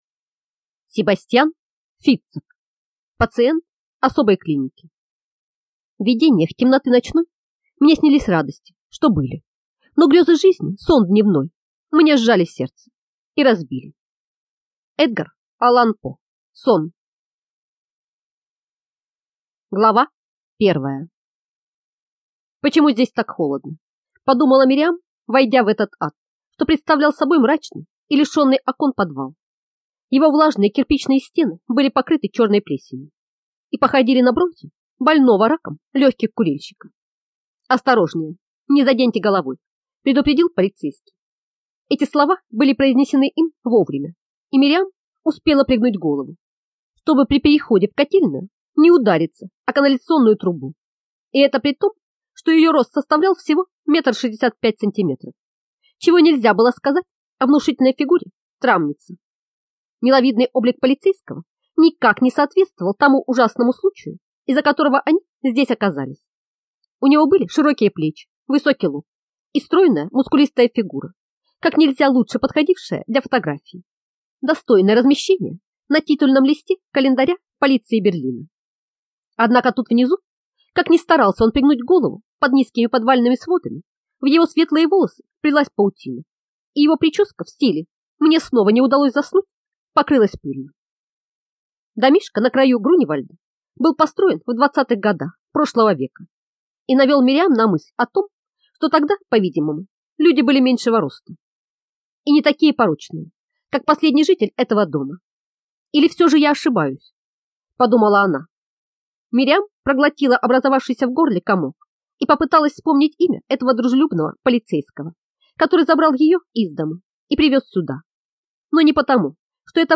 Аудиокнига Пациент особой клиники - купить, скачать и слушать онлайн | КнигоПоиск